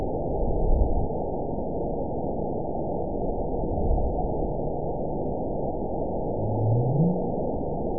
event 920409 date 03/23/24 time 23:34:09 GMT (1 year, 1 month ago) score 9.68 location TSS-AB01 detected by nrw target species NRW annotations +NRW Spectrogram: Frequency (kHz) vs. Time (s) audio not available .wav